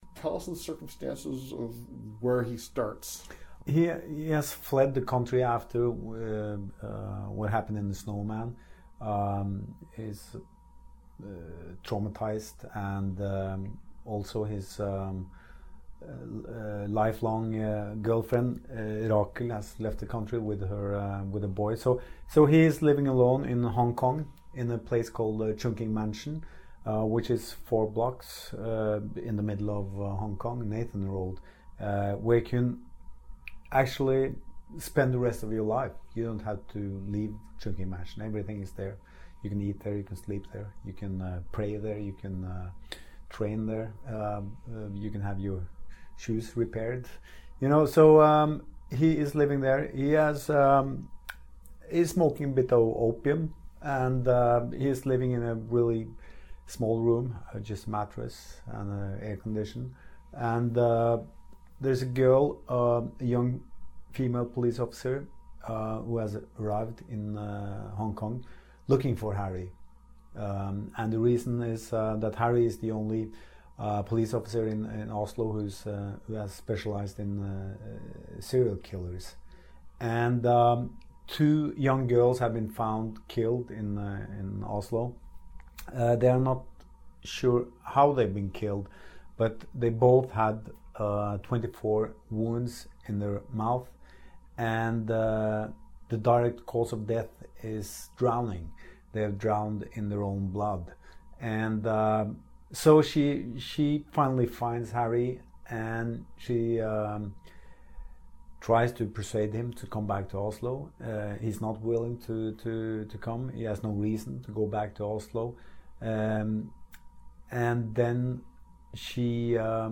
요 네스뵈가 Bookbits 라디오에서 《레오파드》에 대해 이야기하다.